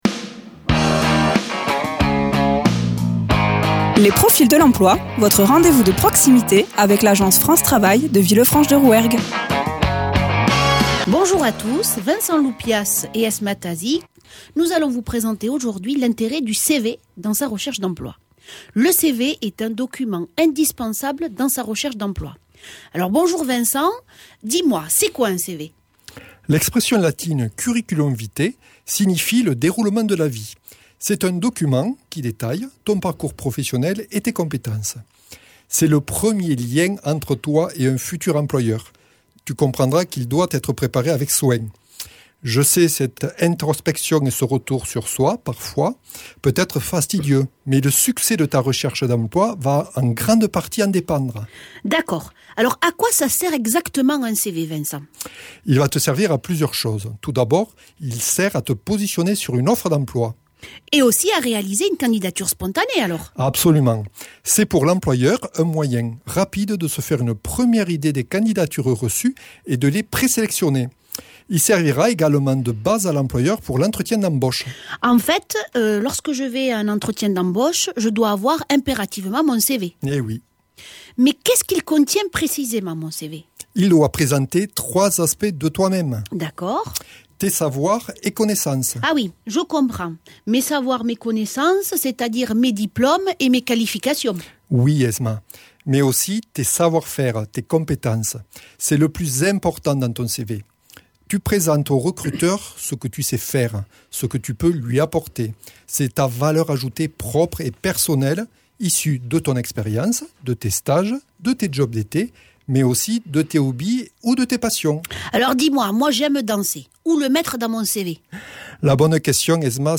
Interviews
Présenté par Les conseillers de France Travail, CFM villefranche